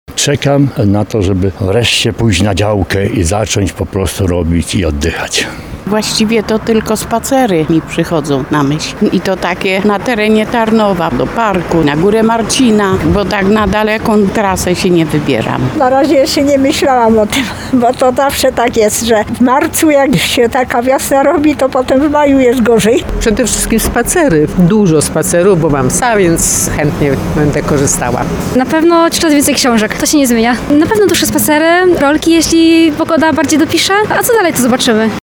W naszej sondzie ulicznej zapytaliśmy Tarnowian, jakie mają plany na pierwsze tygodnie wiosny.